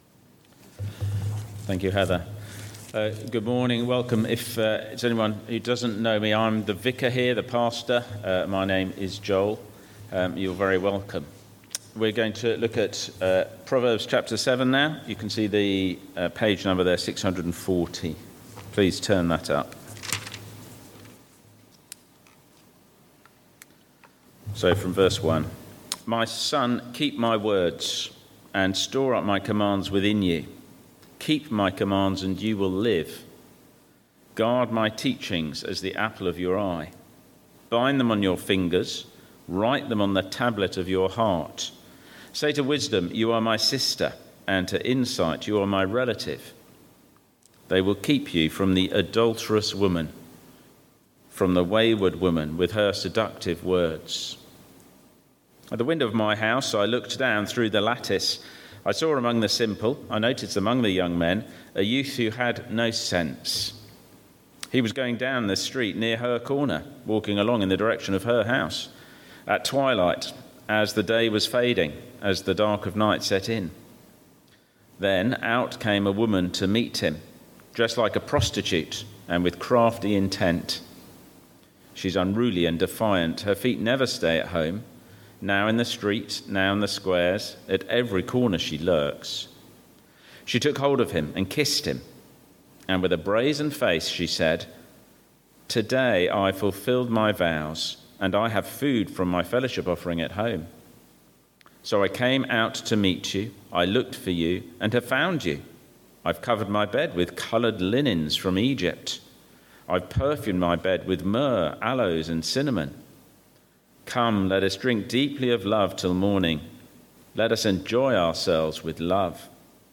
Sermons – Dagenham Parish Church
Service Type: Sunday Morning